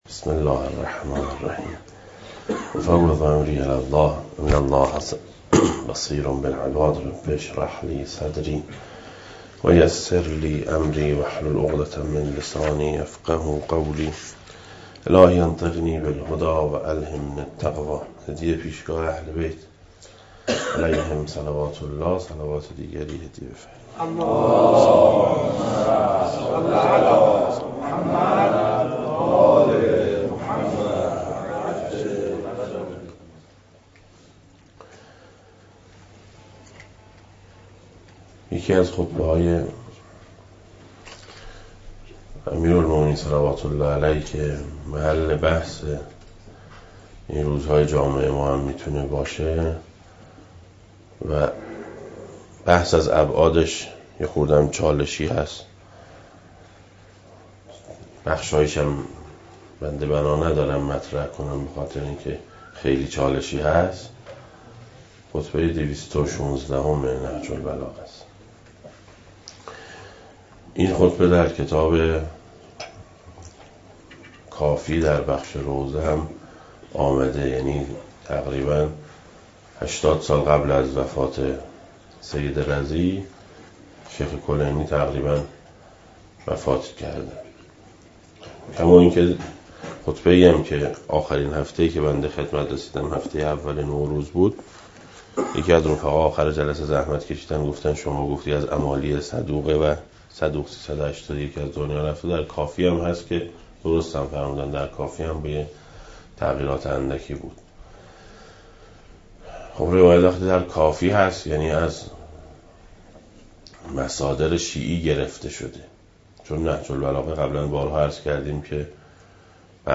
دسته: امیرالمومنین علیه السلام, درآمدی بر نهج البلاغه, سخنرانی ها